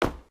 sounds / material / human / step / t_wood1.ogg
t_wood1.ogg